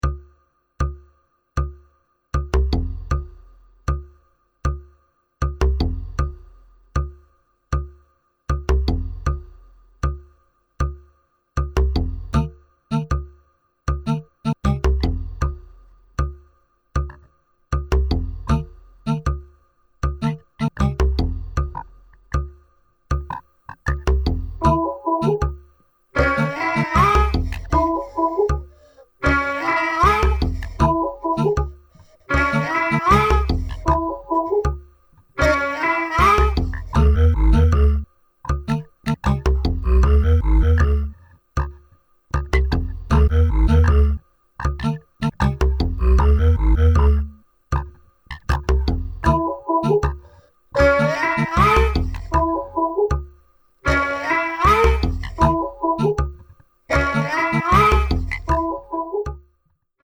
明石とベルリンの遠隔セッションで制作されたという本作